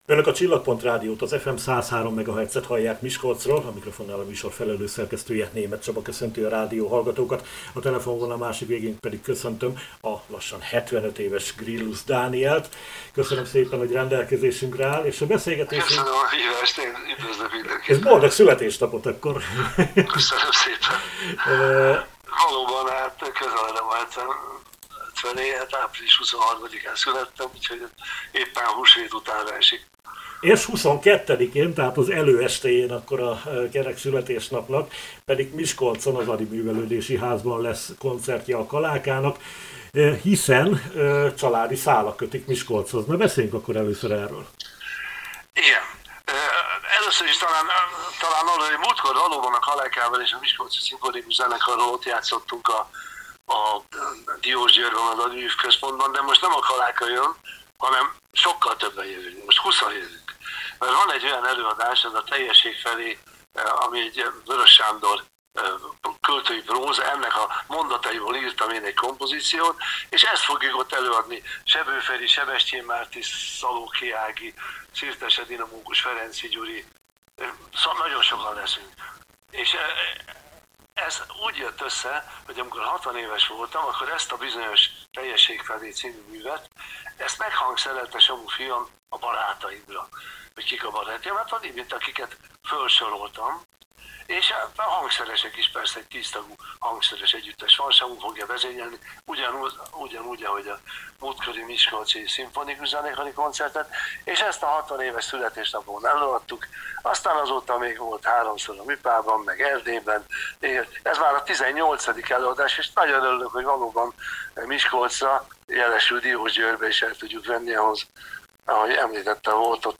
Gryllus Dániel zeneszerzőt családi szálak kötik Miskolchoz, ezért is örömteli számára, hogy a 75. születésnapja előestéjén itt koncertezhet barátaival. Most nem a Kaláka együttes koncertjére invitáljuk önöket, hanem Weöres Sándor írásaiból összeállított előadói estjére, melyet a zeneszerző fia, Gryllus Samu hangszerelt 20 fős zenekarra. A művésszel